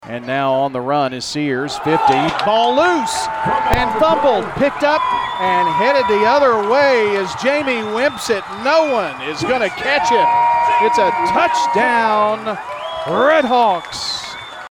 with the call on WGNS State Farm Prep Sports.